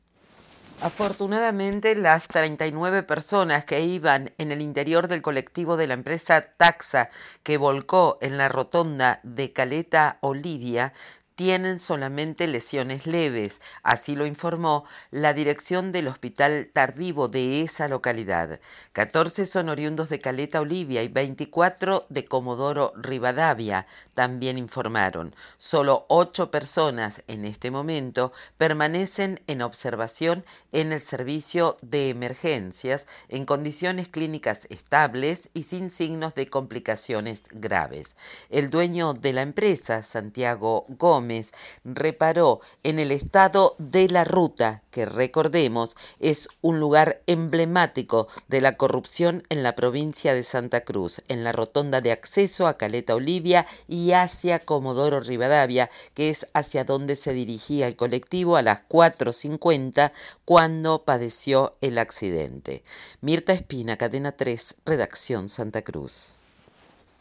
Volcó un colectivo con 39 pasajeros en Caleta Olivia - Boletín informativo - Cadena 3 - Cadena 3 Argentina